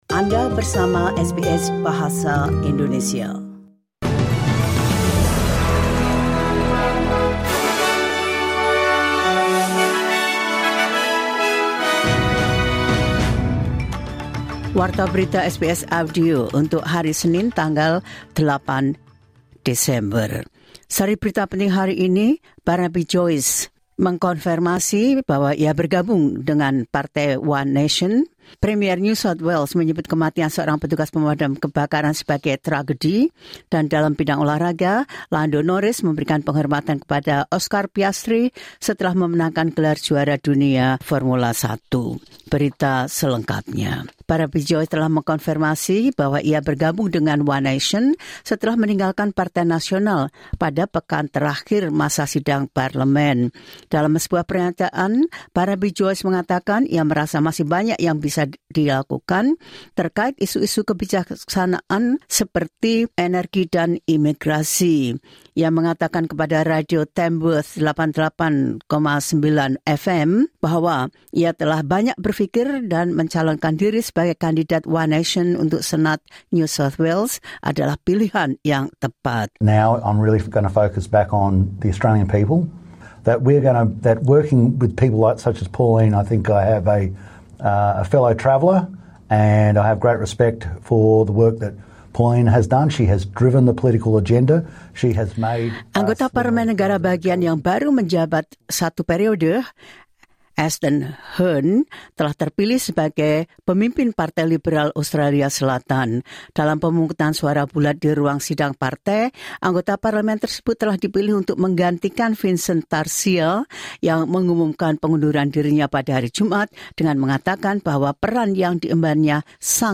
The latest news SBS Audio Indonesian Program – 08 December 2025